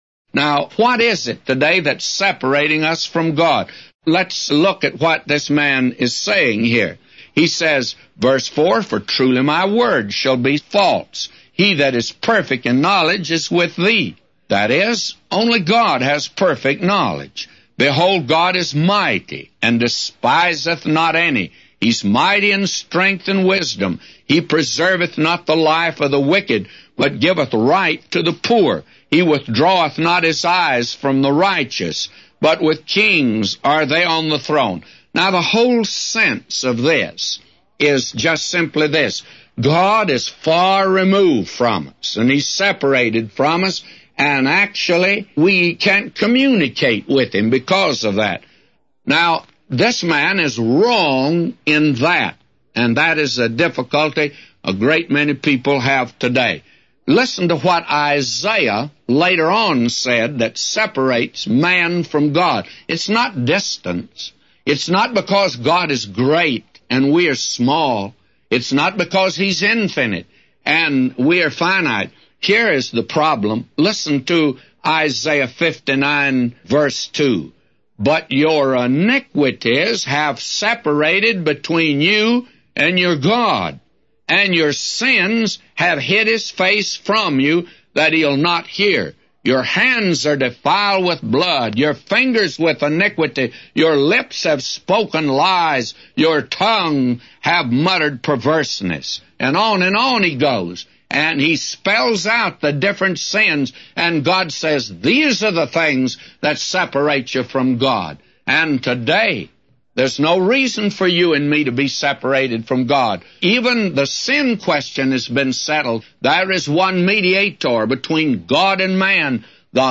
A Commentary By J Vernon MCgee For Job 36:4-999